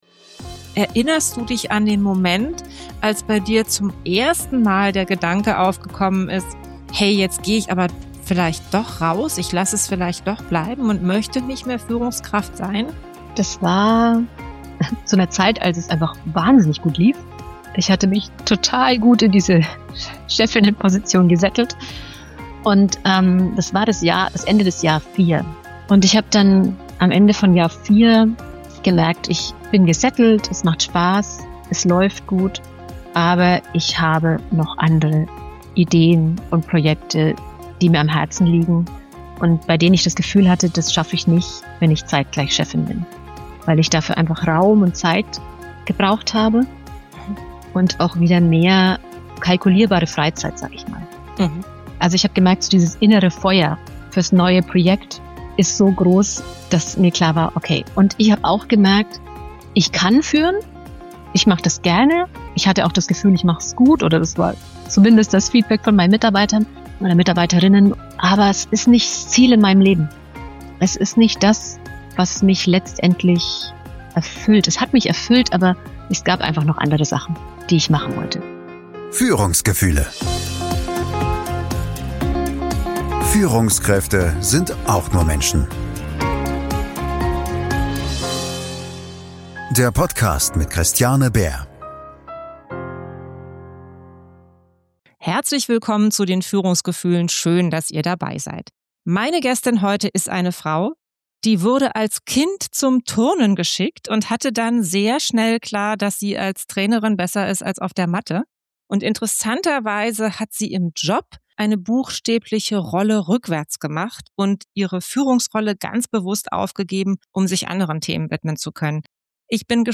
Wir sprechen darüber, wie Angst Entscheidungen treibt, ohne dass man es merkt. Wie man lernt, Krisen zu akzeptieren, statt in der Jammerschleife zu bleiben.